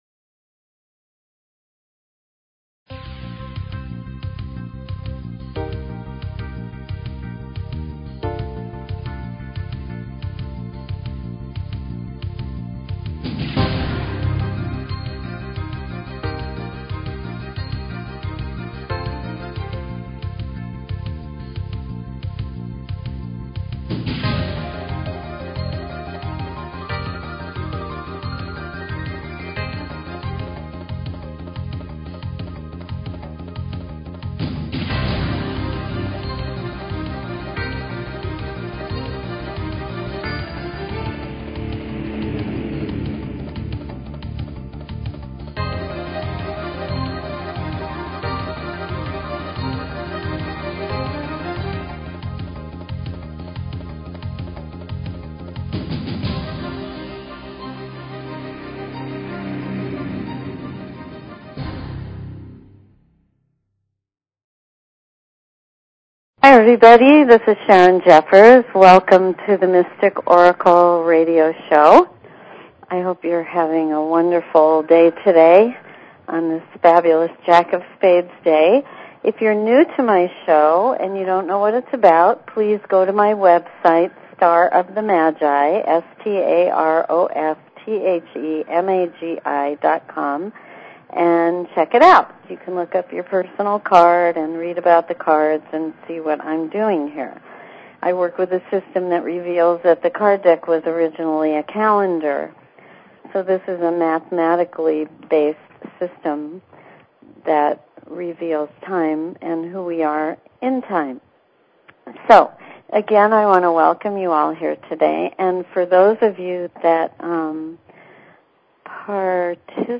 Talk Show Episode, Audio Podcast, The_Mystic_Oracle and Courtesy of BBS Radio on , show guests , about , categorized as
Open lines for calls.